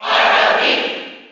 File:R.O.B. Cheer NTSC SSB4.ogg
R.O.B._Cheer_NTSC_SSB4.ogg.mp3